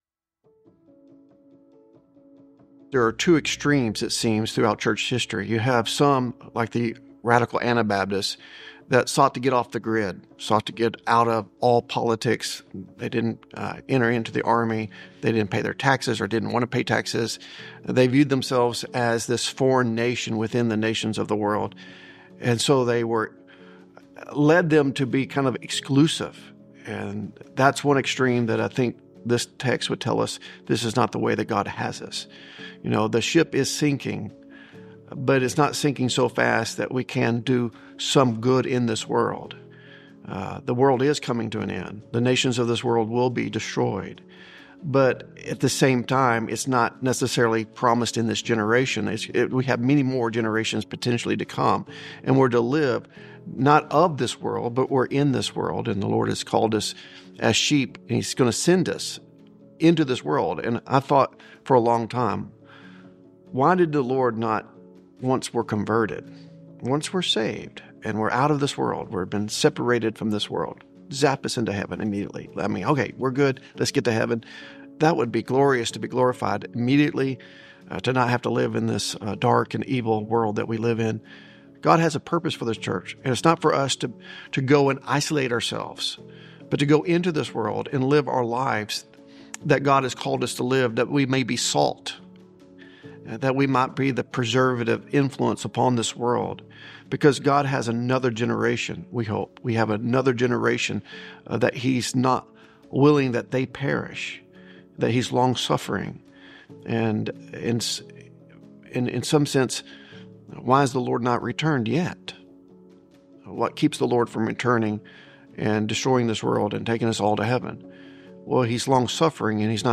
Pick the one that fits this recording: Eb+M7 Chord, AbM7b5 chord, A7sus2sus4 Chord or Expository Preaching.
Expository Preaching